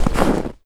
STEPS Snow, Run 13.wav